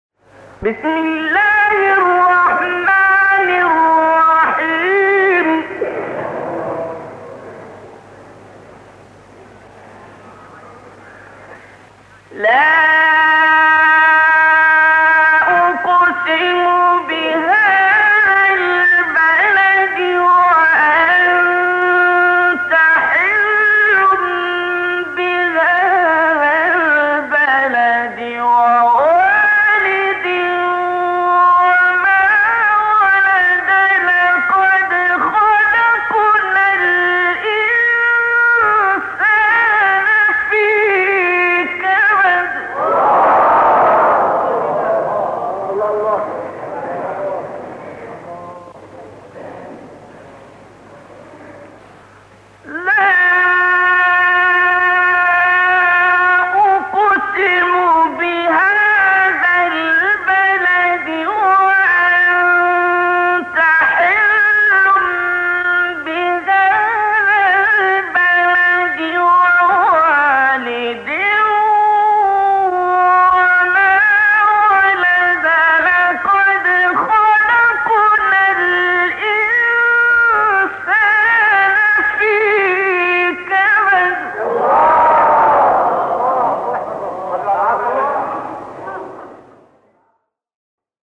قطعه تلاوت عبدالباسط